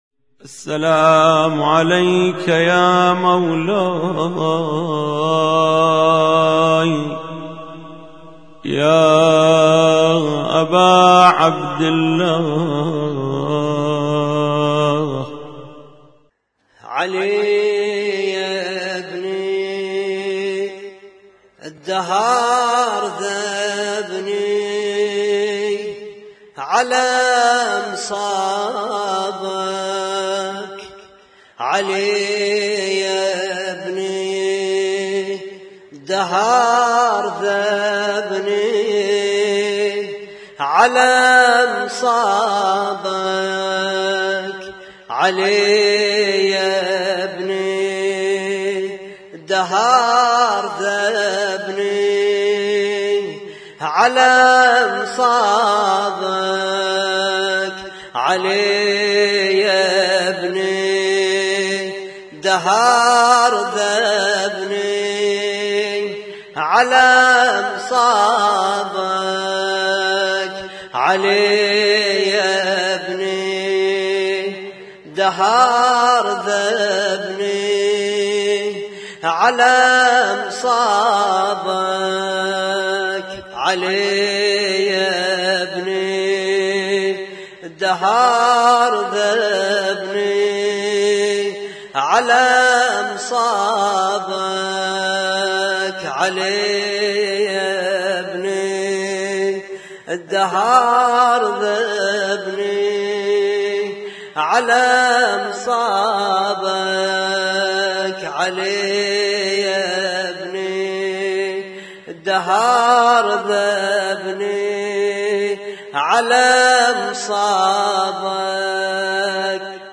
اسم النشيد:: لطم مشترك - ليلة 9 محرم 1436